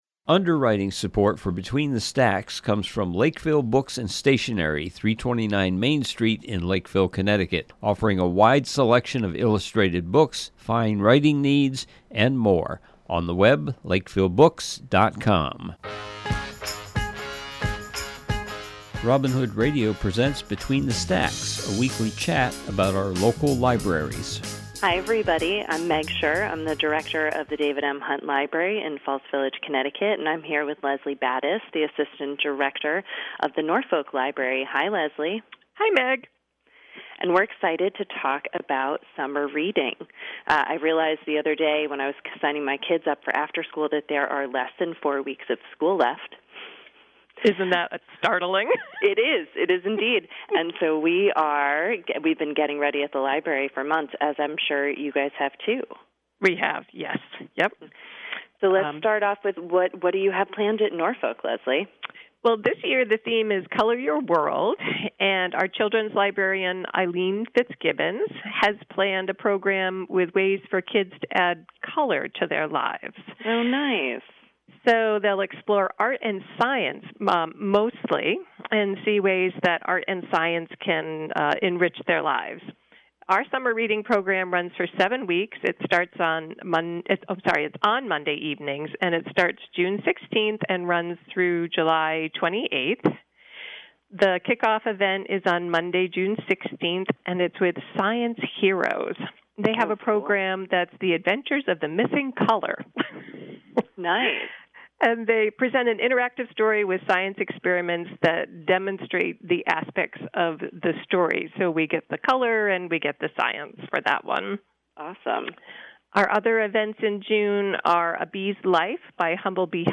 This week’s program is a conversation